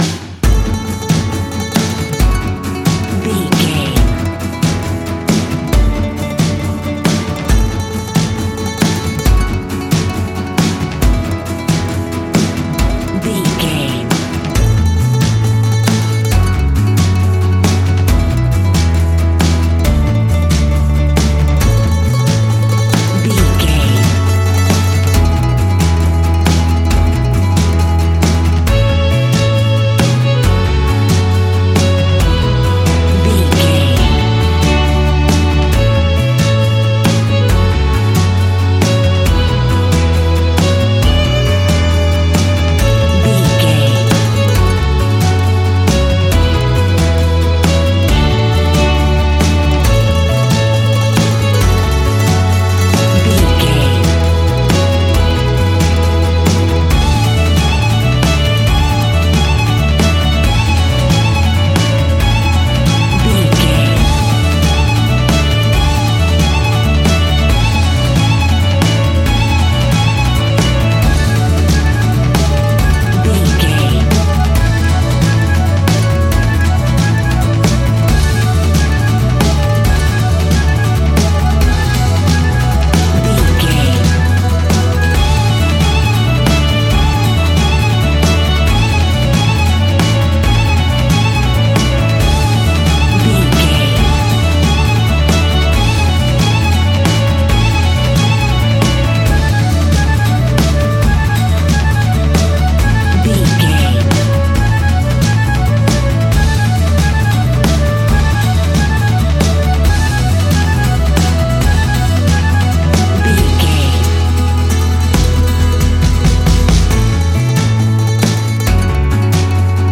Ionian/Major
acoustic guitar
mandolin
drums
double bass
accordion